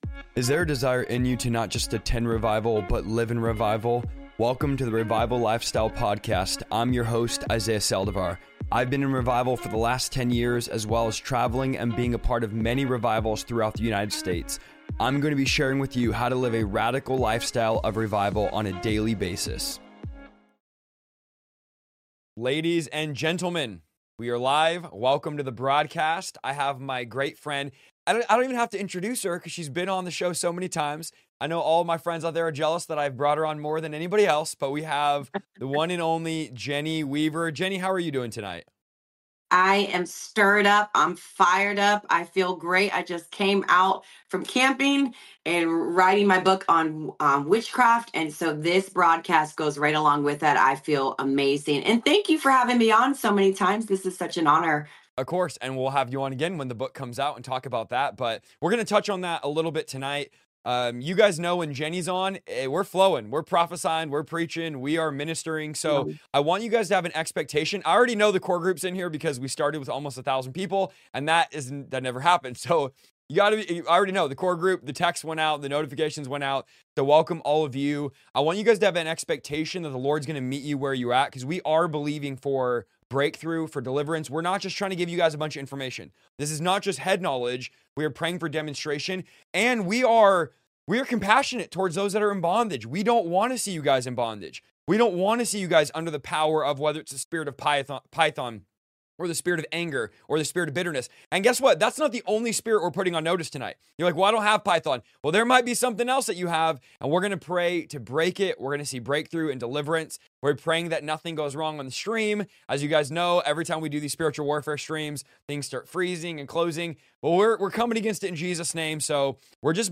But through the power of Jesus, you can be set free! In this powerful livestream